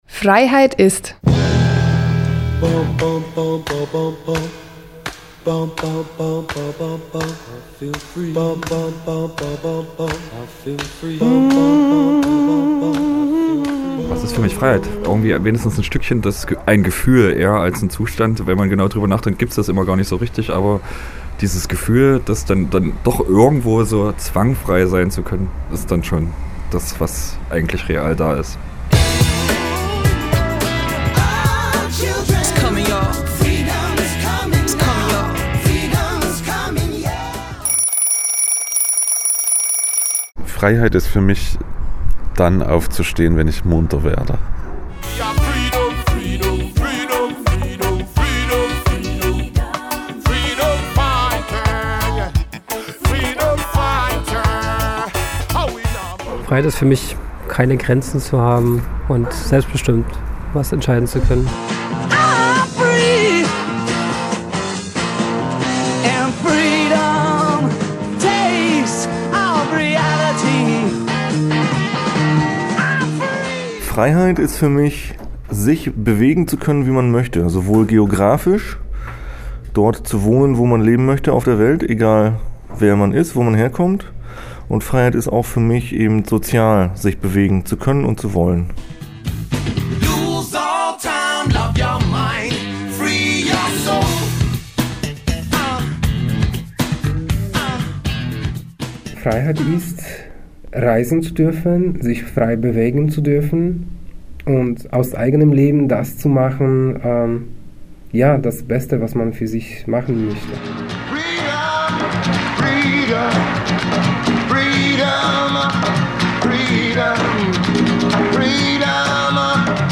(German only)